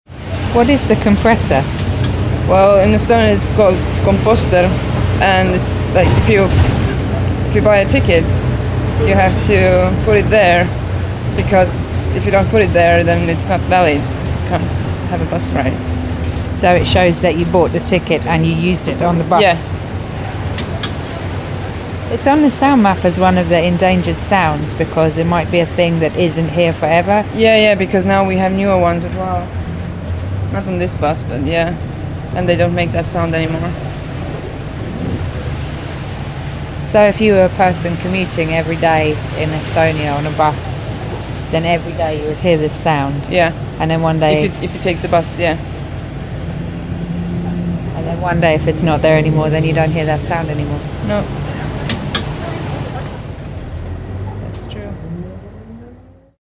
KOMPOSTER - the endangered sound of the bus ticket hole-puncher in Tallinn